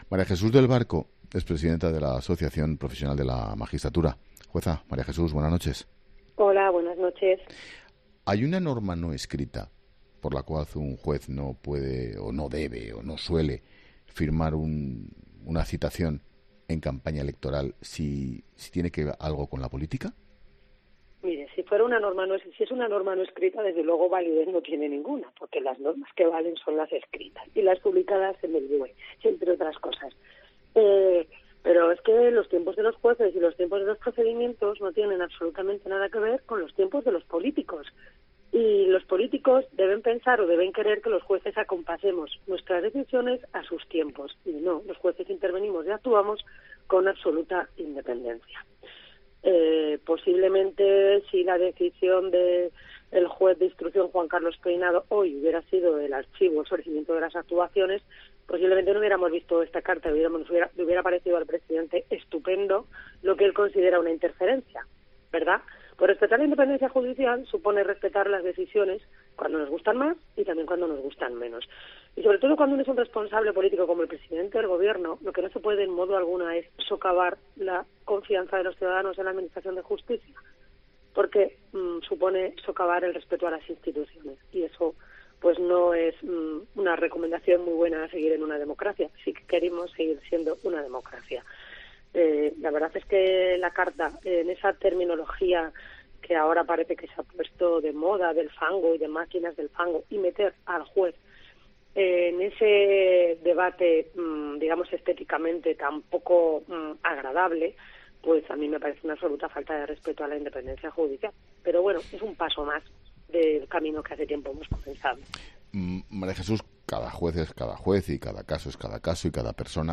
María Jesús del Barco es jueza y presidenta de la Asociación Profesional de la Magistratura, y ha analizado este martes en La Linterna la carta del presidente del Gobierno, Pedro Sánchez, tras saberse que su mujer, Begoña Gómez, está citada a declarar para el próximo 5 de julio.